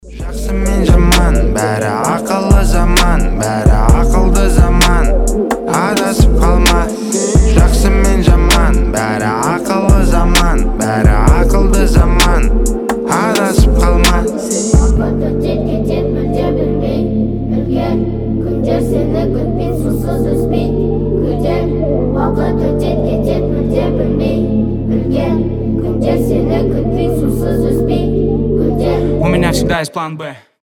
• Качество: 320, Stereo
детский голос
хор
патриотические